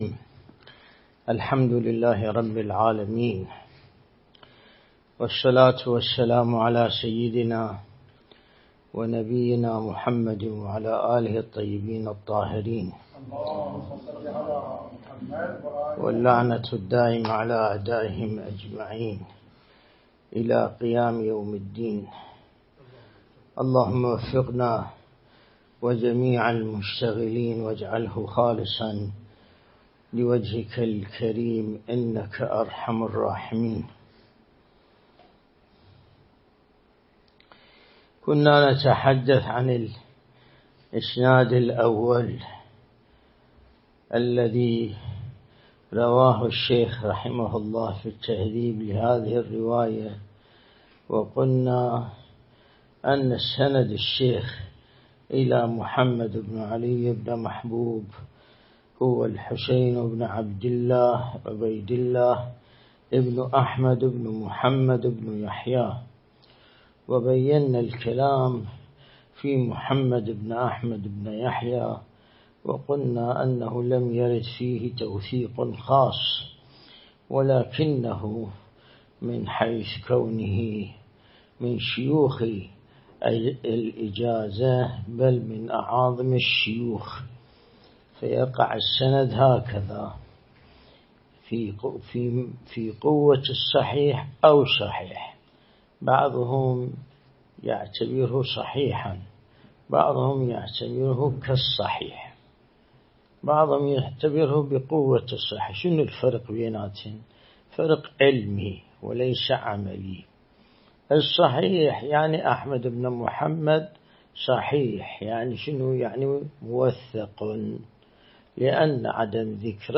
الدرس الاستدلالي شرح بحث الطهارة من كتاب العروة الوثقى لسماحة آية الله السيد ياسين الموسوي(دام ظله)